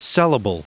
Prononciation du mot sellable en anglais (fichier audio)
Prononciation du mot : sellable